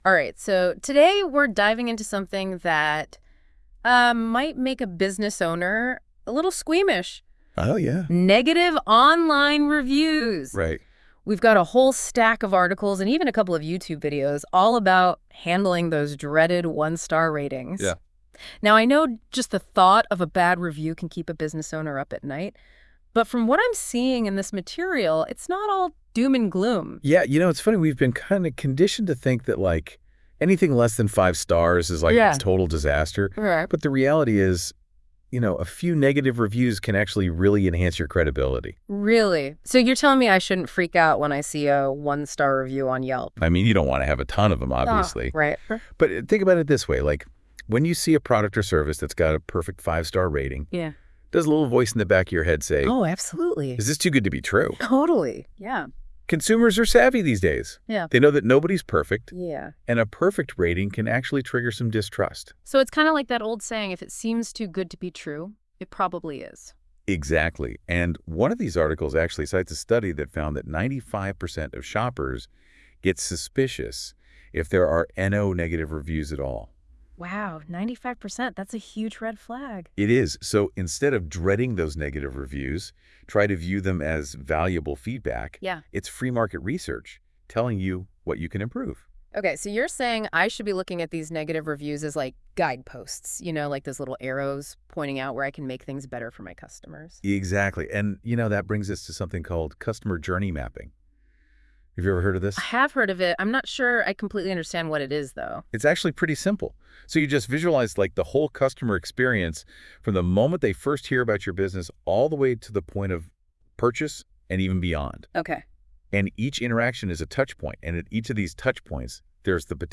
In this Deep DIVE: Your friendly FIVE75 AI hosts talk about negative reviews!